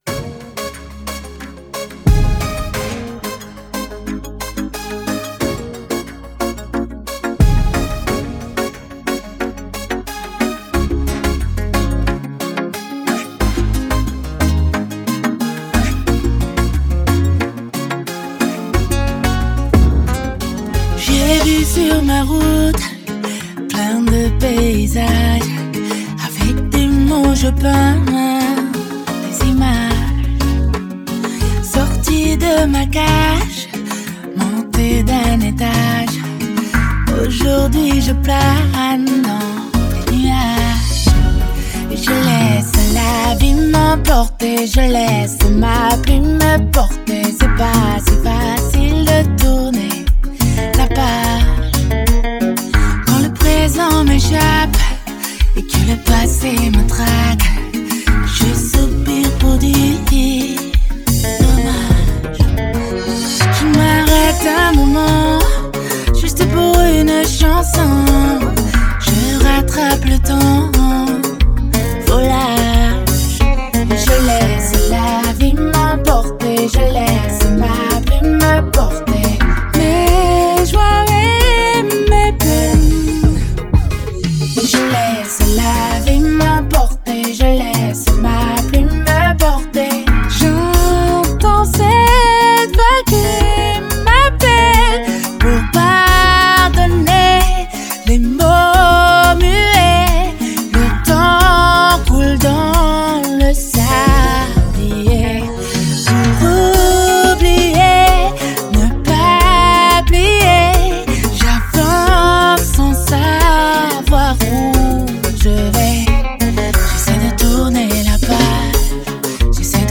musique salsa bachata kizomba mix remix